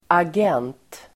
Uttal: [ag'en:t]